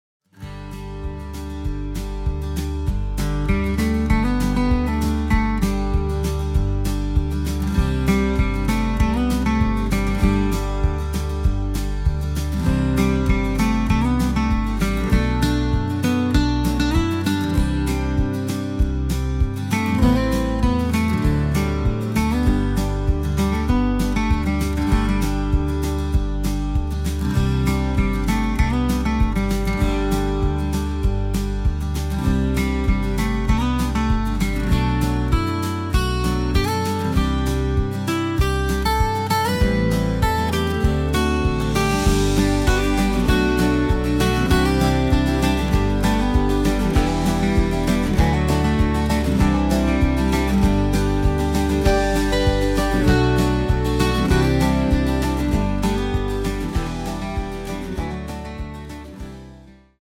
Leichter Countrystyle
Musik